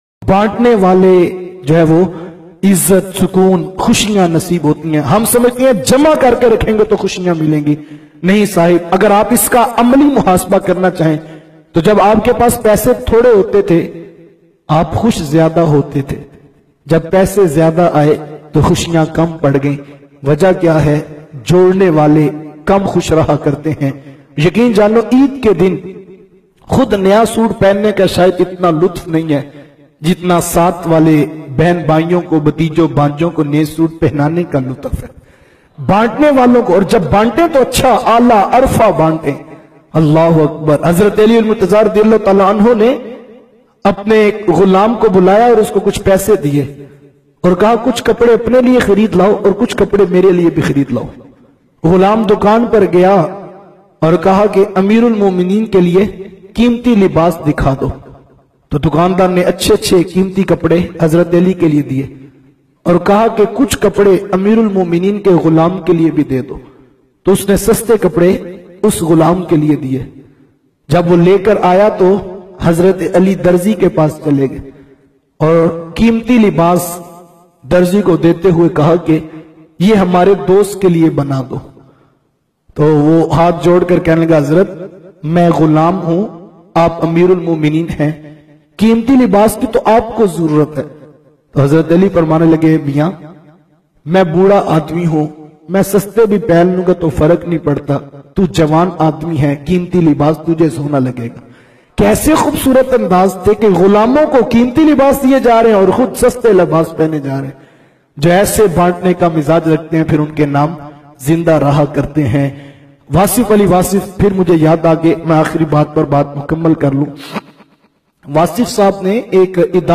Khushiya Batne walo ki Zindagi bayan mp3